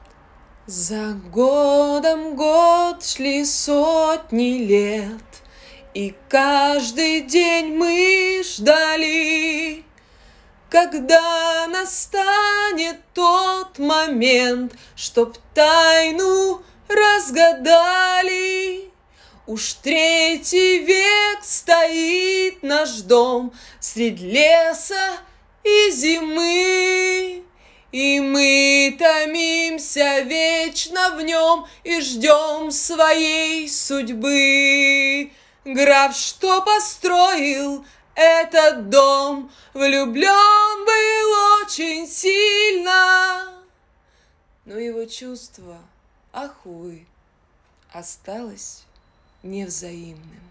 Женский
Певческий голос
Сопрано